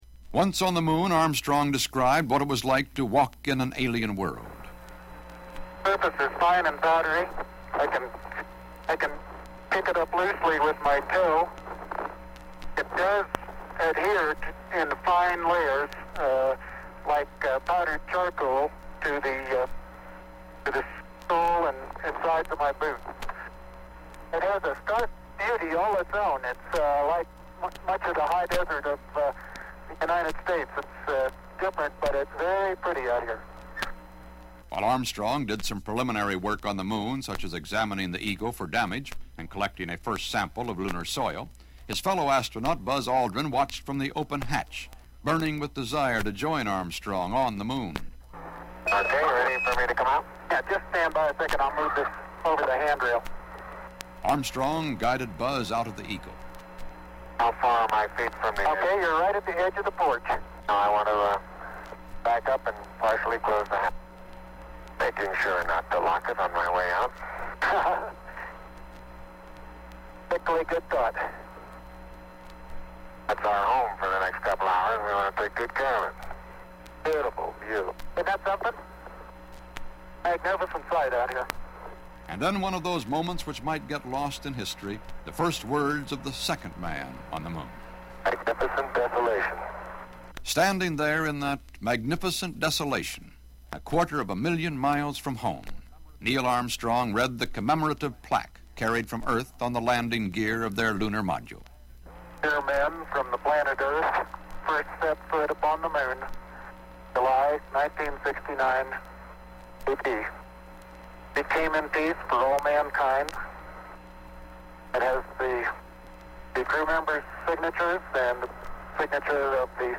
So I have digitized some 33 RPMs. :D This post is a birthday present for my sister, who has said I should do some of these, but the rest of you are welcome to share them too.
Man On The Moon/CBS Enterprises/Narrated by Walter Cronkite
This is a very small 33 RPM record issued by CBS News/Enterprises; I can't find a date on it.